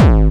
VEC3 Bassdrums Dirty 20.wav